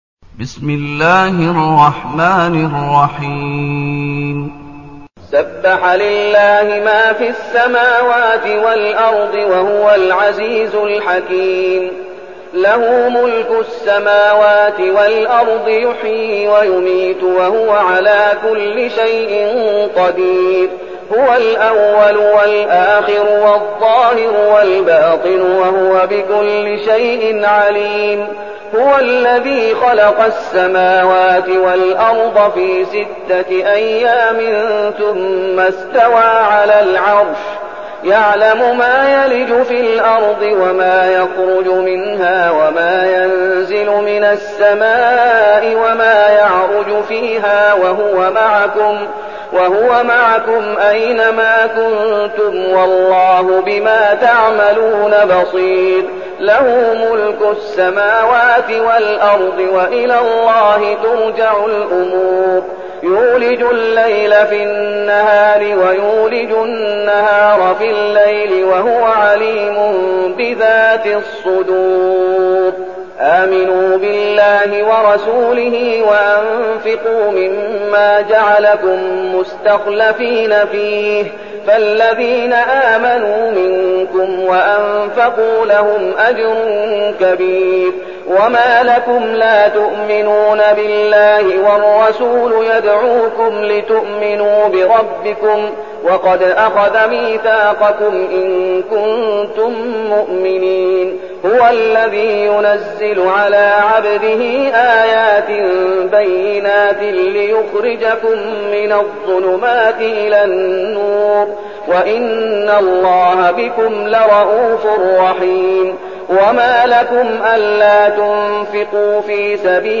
المكان: المسجد النبوي الشيخ: فضيلة الشيخ محمد أيوب فضيلة الشيخ محمد أيوب الحديد